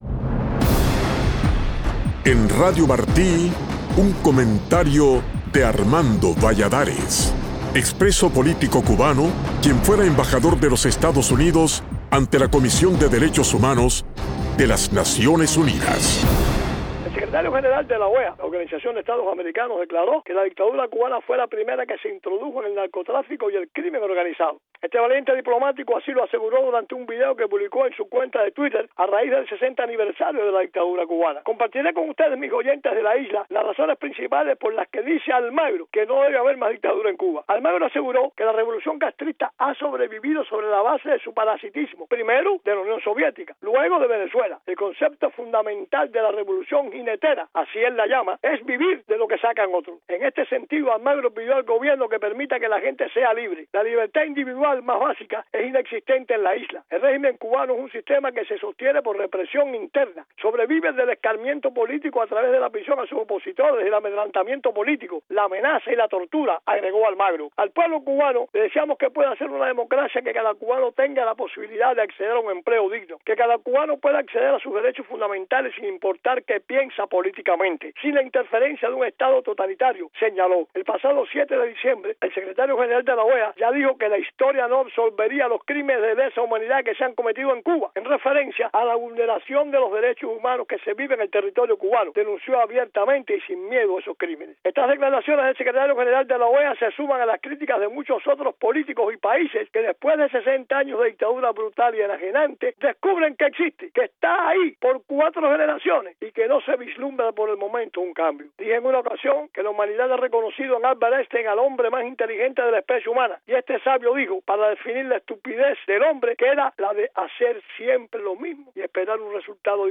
Comparto con ustedes en este comentario las razones por las que dice Almagro no debe haber más dictadura en Cuba...